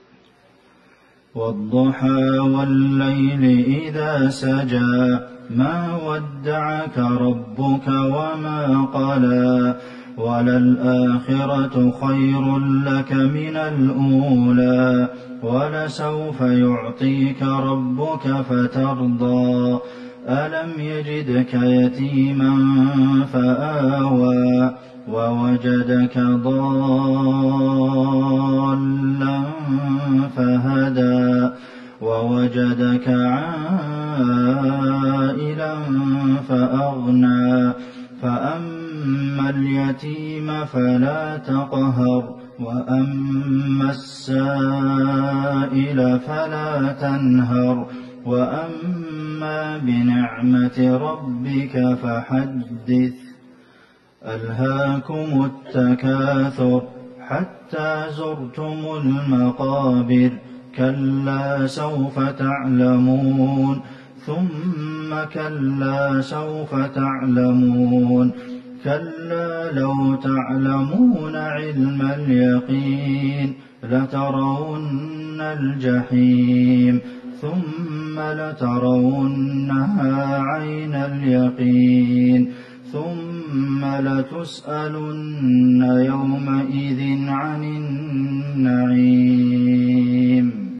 صلاة المغرب ١٤٤١/١/٨ سورتي الضحى والتكاثر | Maghrib prayer Surah adh-Duha and At-Takathur > 1441 🕌 > الفروض - تلاوات الحرمين